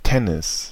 Ääntäminen
Synonyymit court court de tennis terrain de tennis basket Ääntäminen France: IPA: [te.nis] Haettu sana löytyi näillä lähdekielillä: ranska Käännös Konteksti Ääninäyte Substantiivit 1.